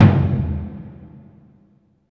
Index of /90_sSampleCDs/Roland LCDP14 Africa VOL-2/PRC_Burundi Drms/PRC_Burundi Drms